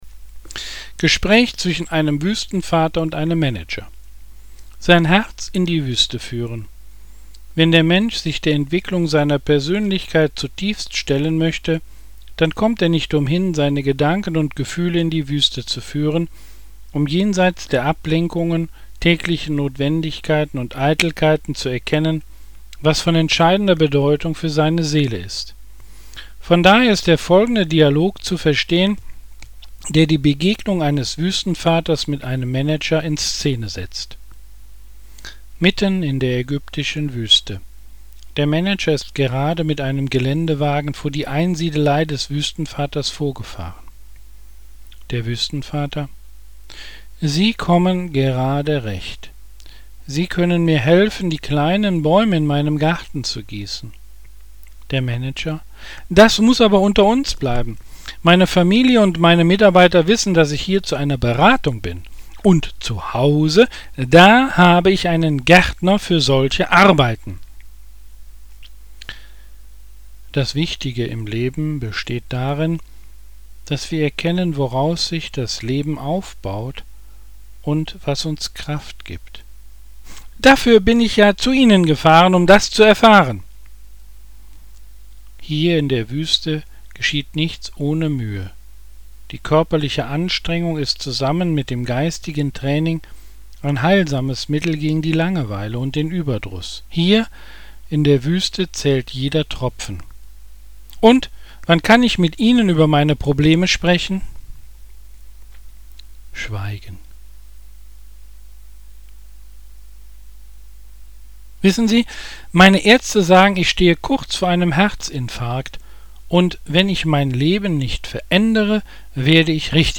Textlesung 2
gespraechwuestenvater.mp3